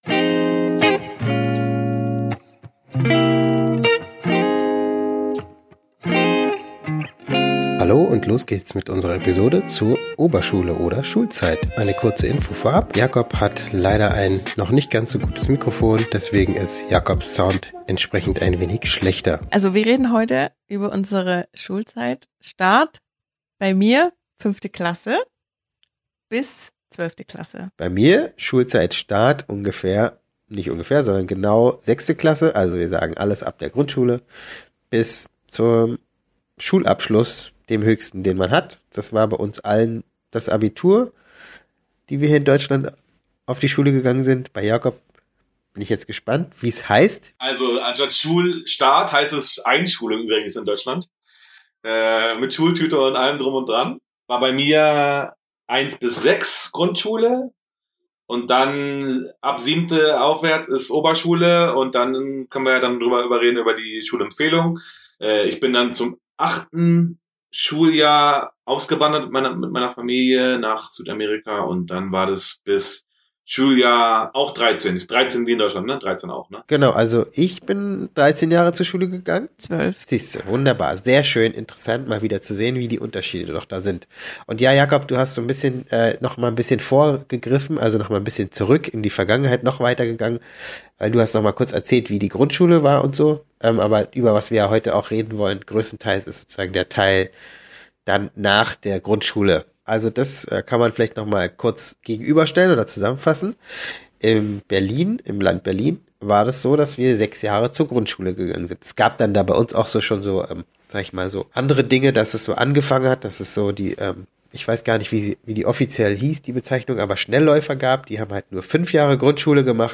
Beim nächsten Mal sollte es dann wieder weniger nasal werden.